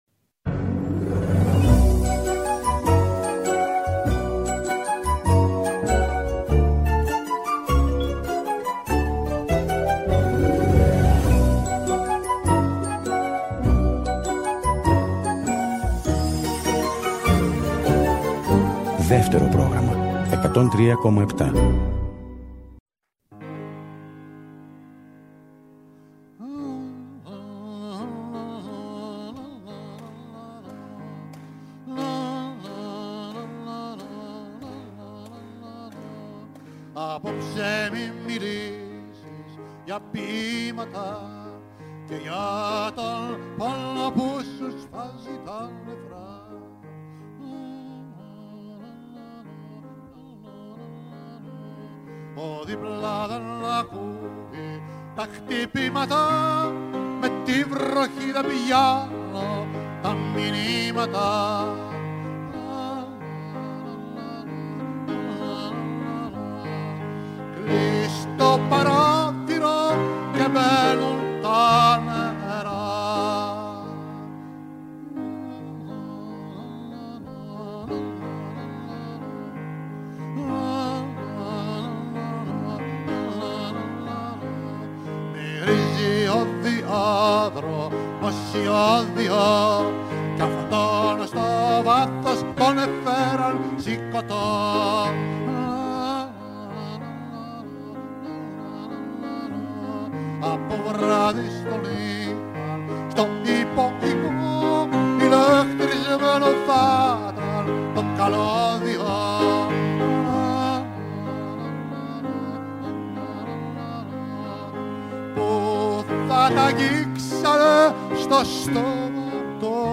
Το σημερινό “Κλειδί του sol” είναι αφιερωμένο στην 50η επέτειο από την εξέγερση του Πολυτεχνείου με σχετικά τραγούδια και στιγμιότυπα παλαιότερων ραδιοφωνικών αφιερωμάτων στο Πολυτεχνείο